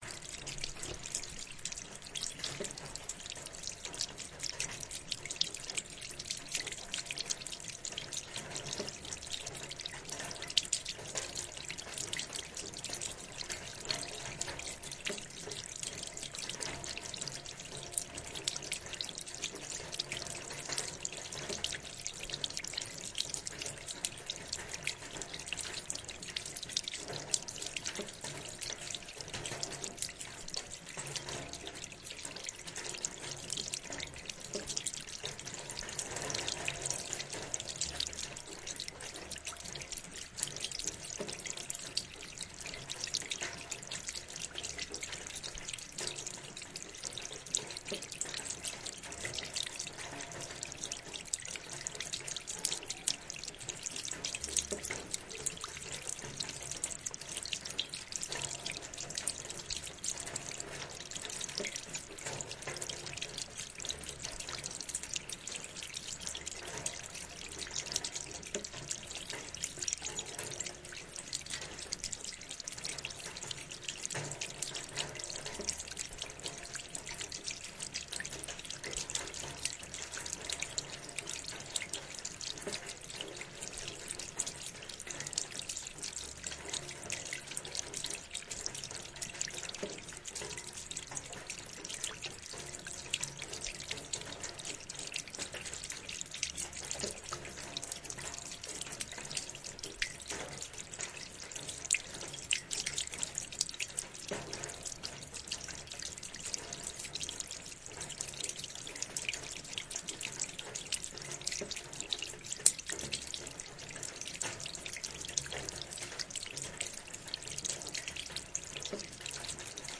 威尼斯声音/接触麦克风/水听器/" 排水管接触麦克风雨威尼斯
描述：排水管接触麦克雨威尼斯
Tag: 排水管 威尼斯 接触式麦克风